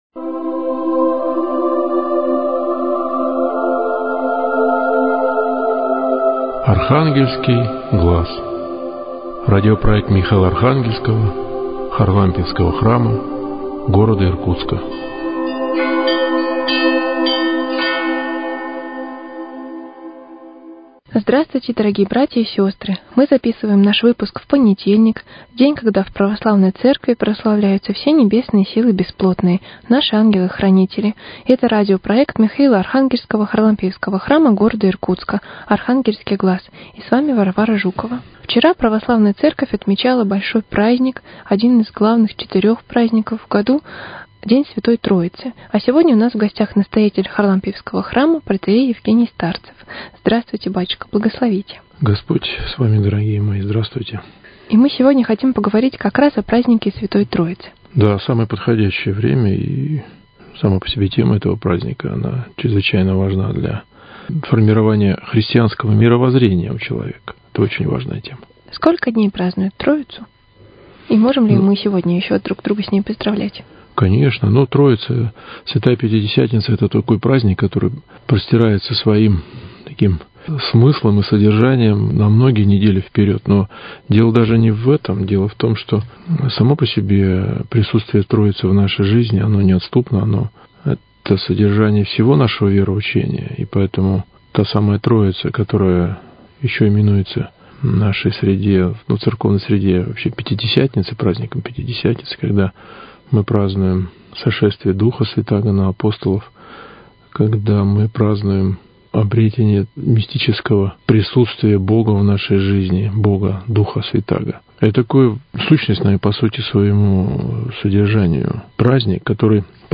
Вашему вниманию беседа о празднике Святой Троицы, Церкви и Вере.